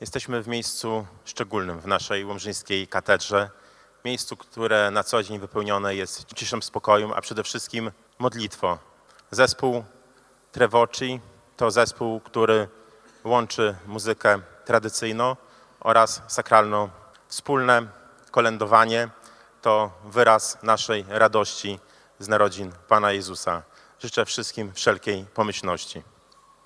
Prezydent Łomży Mariusz Chrzanowski był pod wrażeniem atmosfery koncertu.